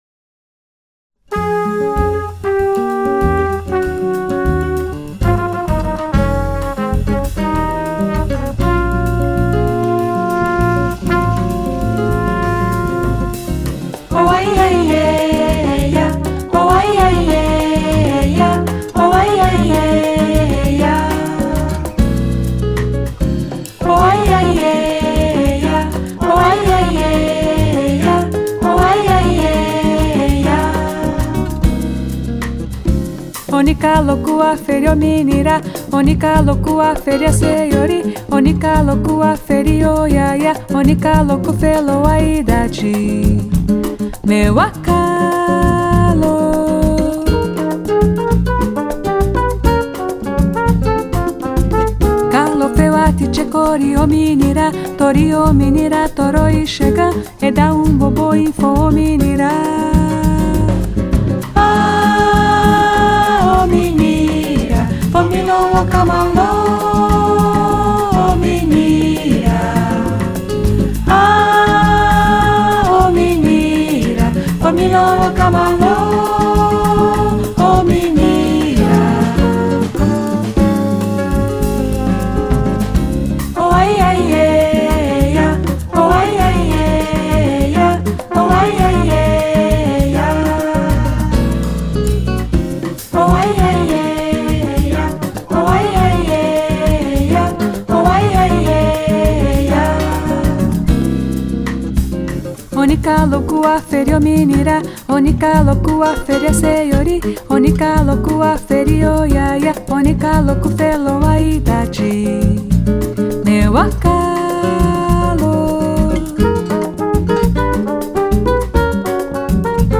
閒適怡人、無拘無束的音樂，搭配上她時而慵懶柔軟、時而活潑俏皮的唱腔，
音樂類型：爵士樂 - Bossa Nova[center]